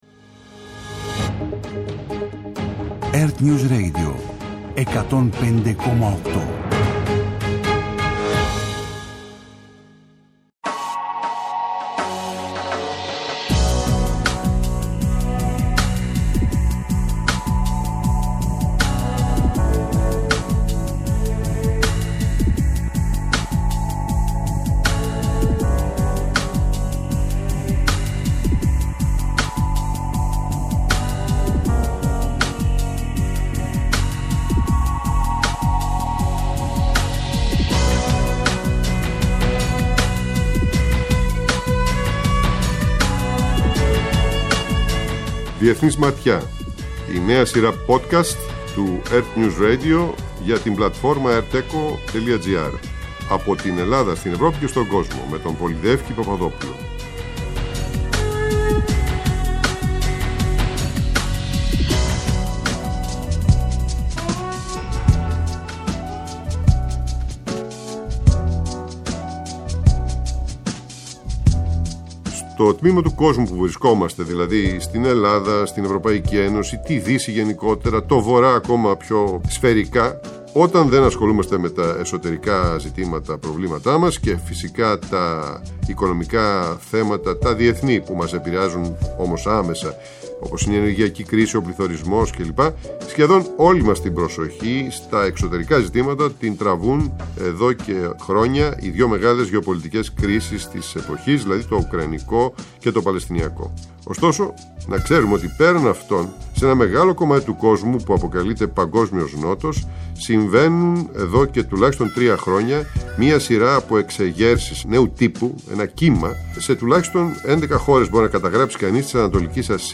Συζήτηση με την Επίκουρη Καθηγήτρια Συγκριτικής Πολιτικής